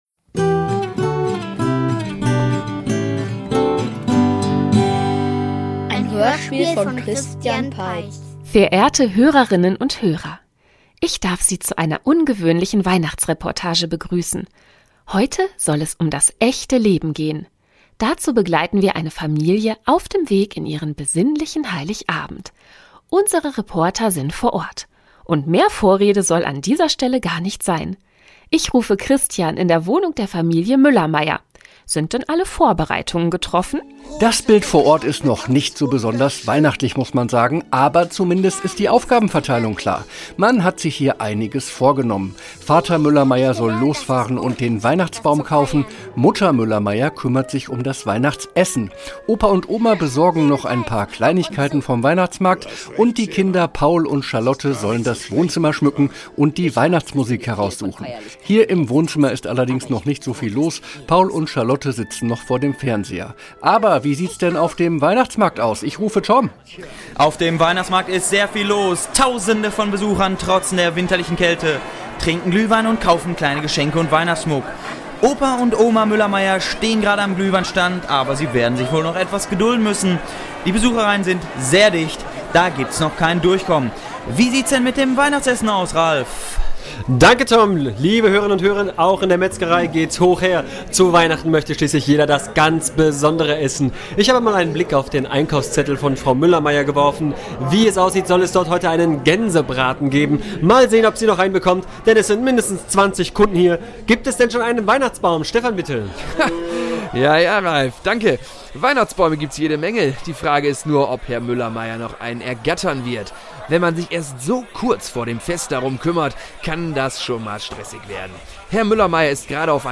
Weihnachtsfinale --- Weihnachtshörspiel #04 ~ Märchen-Hörspiele Podcast
Kein Märchen, sondern eine Reportage, quasi aus dem echten Leben. Vier Reporter begleiten die Familie Müllermeier bei den Vorbereitungen auf den besinnlichen Heiligabend – und geraten in ein zunehmend chaotisches Geschehen.